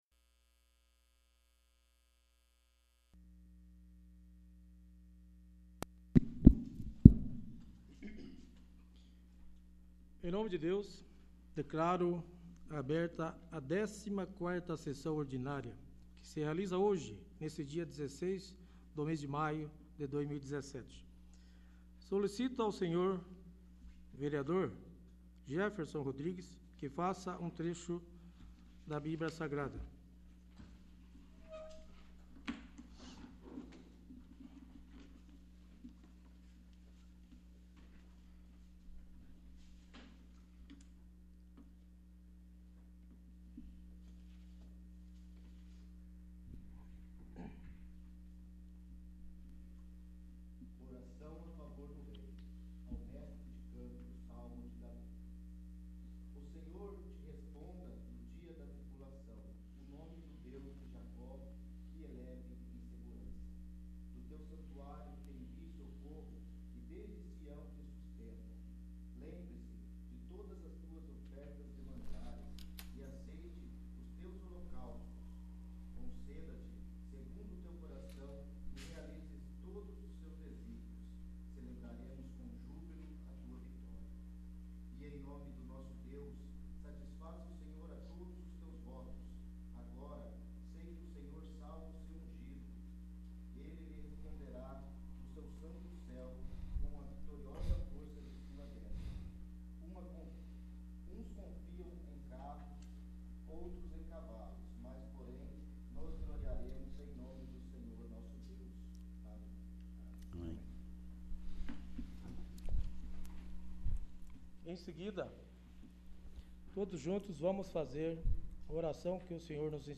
14º. Sessão Ordinária 16/05/2017
14º. Sessão Ordinária